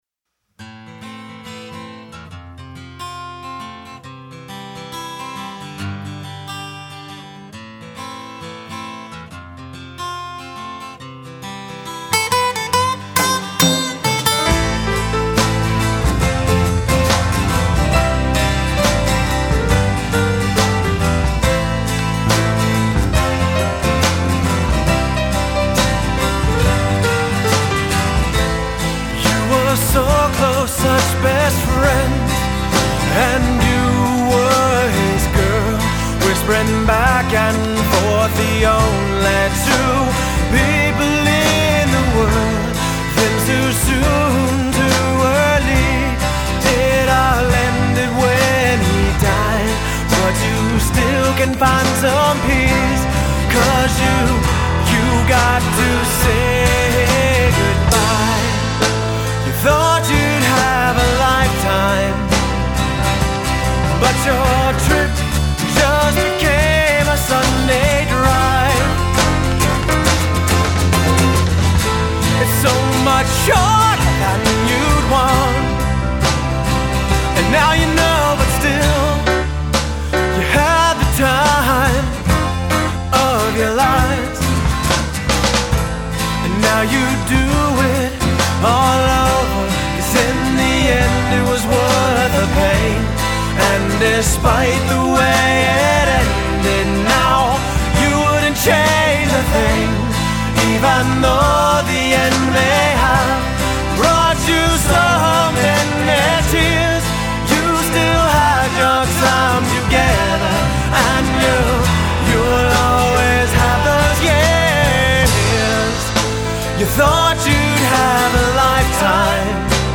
Guitar, Bass Guitar, Vocals
Drums
Piano